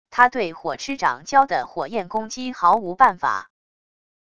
他对火蚩掌教的火焰攻击毫无办法wav音频生成系统WAV Audio Player